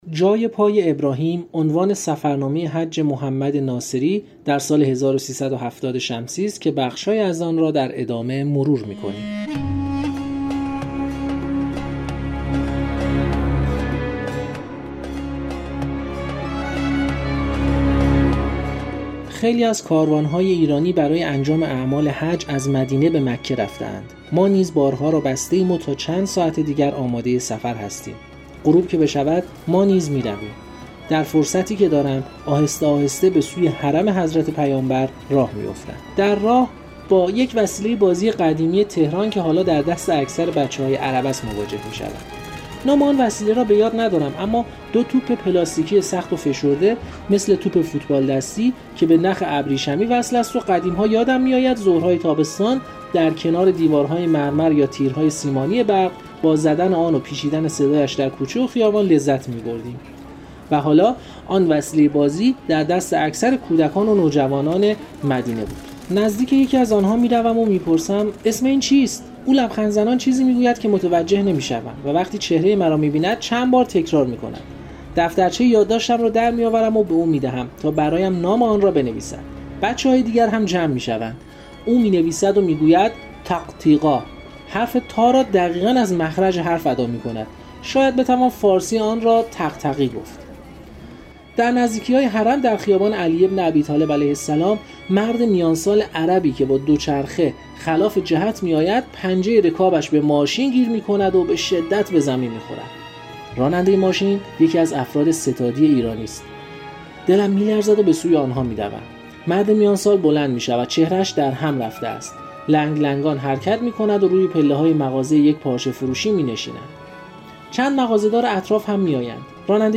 در بیست و یکمین قسمت از این مجموعه پادکست‌ها، بخش‌هایی از کتاب « جای پای ابراهیم» که سفرنامه حج «محمد ناصری» در سال ۱۳۷۰ شمسی است را می‌شنویم.